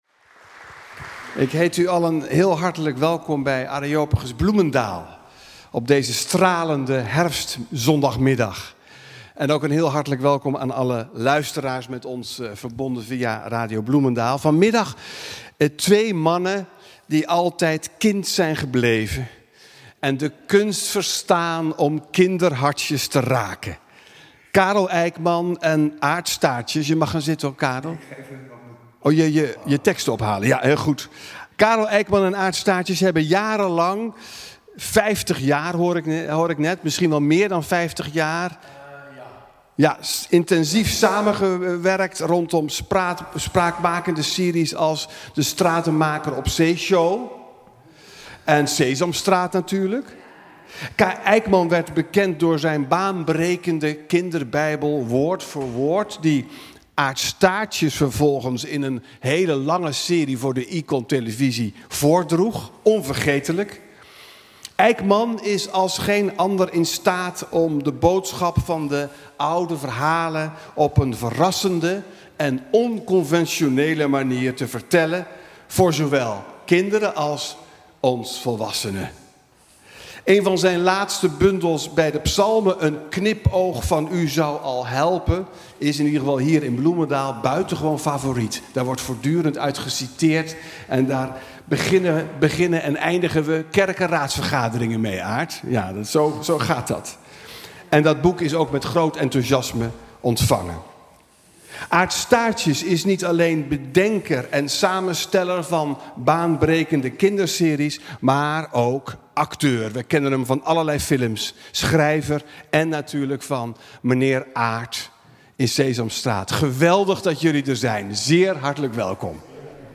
Een gesprek in de serie Areopagus Bloemendaal tussen Aart Staartjes en Karel Eykman.